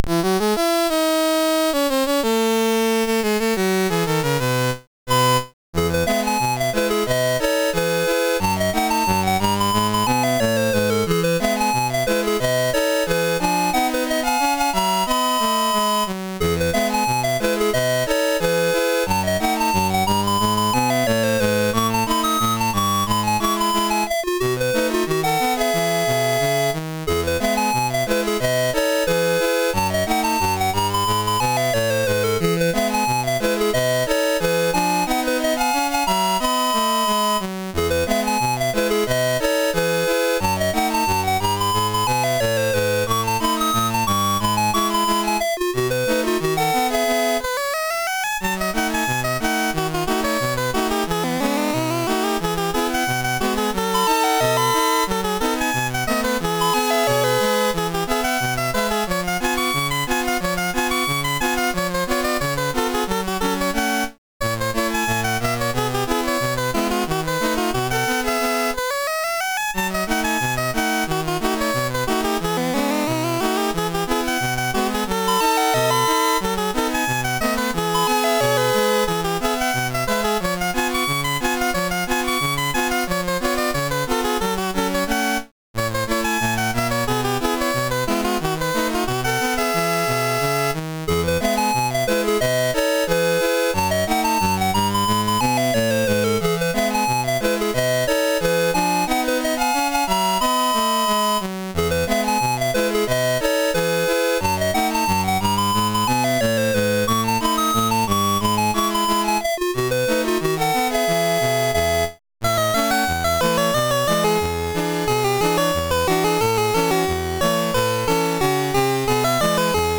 home *** CD-ROM | disk | FTP | other *** search / Loadstar 243 / 243.d81 / somethindoin.mus ( .mp3 ) < prev next > Commodore SID Music File | 2022-08-26 | 2KB | 1 channel | 44,100 sample rate | 3 minutes